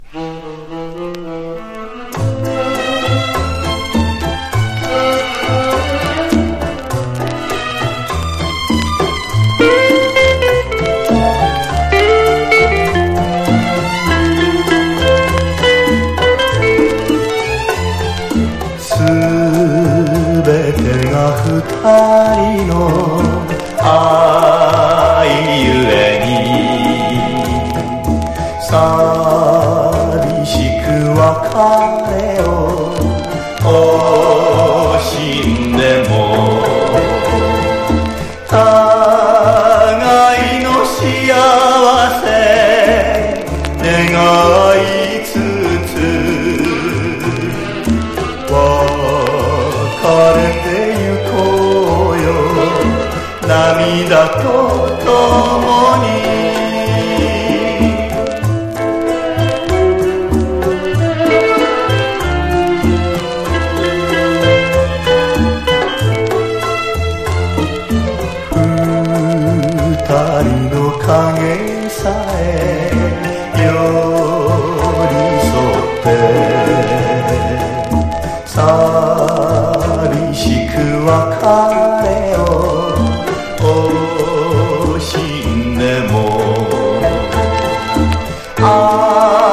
和製ラウンジ・ミュージックの元祖
艶やかなムード歌謡
和モノ / ポピュラー